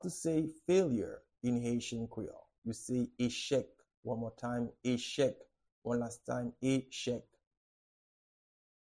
How-to-say-Failure-in-Haitian-Creole-Echek-pronunciation.mp3